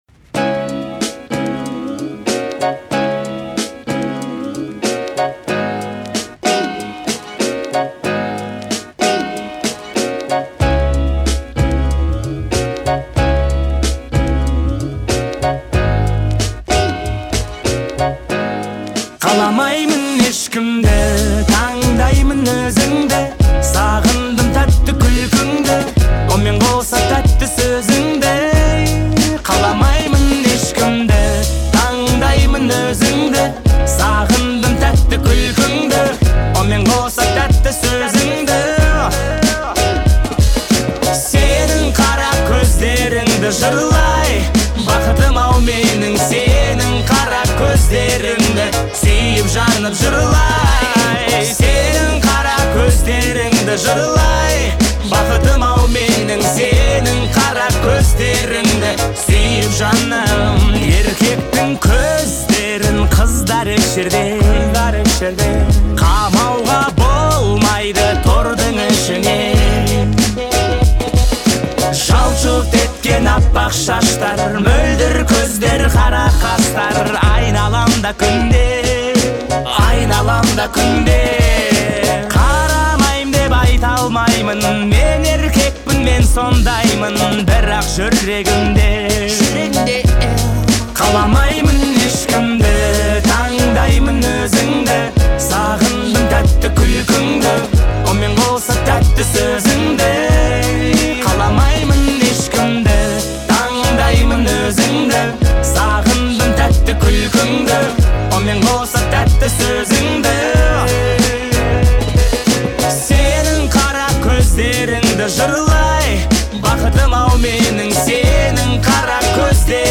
кто ценит искренние тексты и мелодичное звучание.